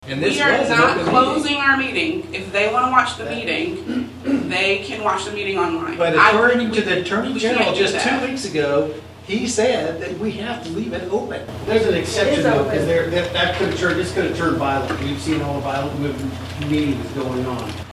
Tensions rose between the public during Wednesday’s USD 383 Manhattan-Ogden school board meeting.
After Coleman asked the public to leave, concerns were brought up by other board members on the logistics of the Kansas Open Meetings Act.